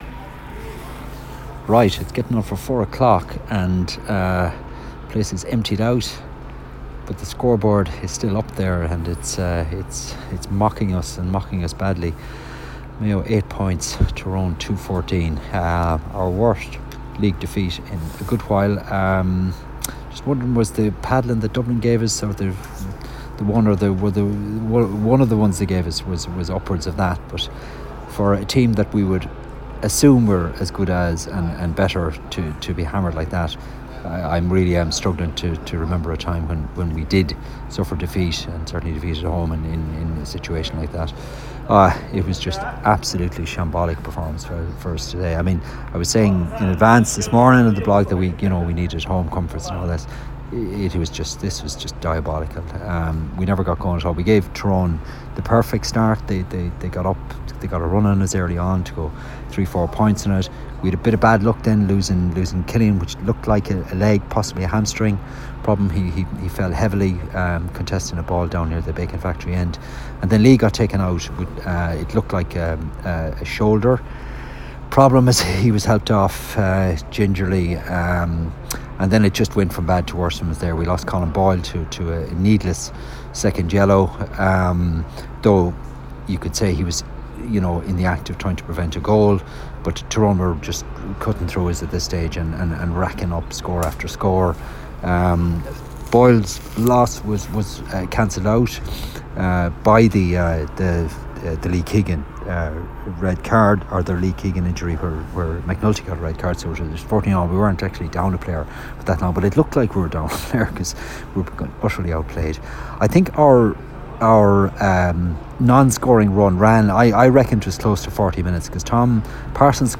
Mayo 0-8 Tyrone 2-14 audio report